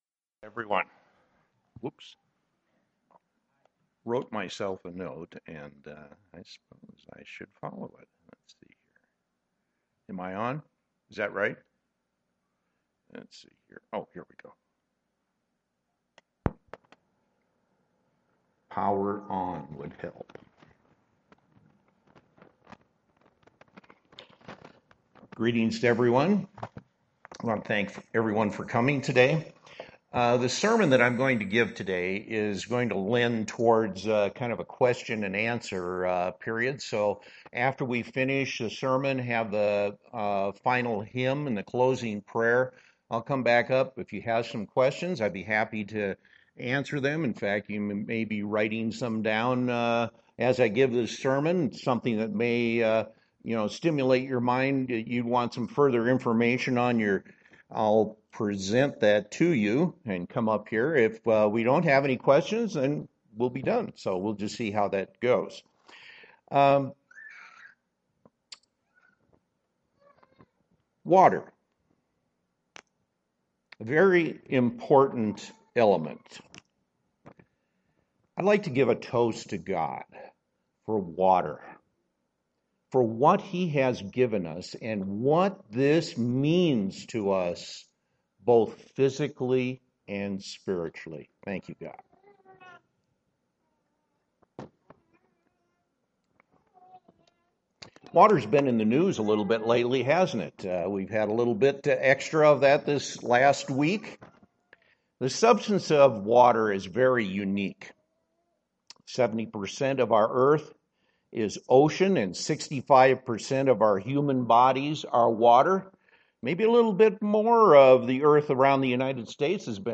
Given in Denver, CO
UCG Sermon Studying the bible?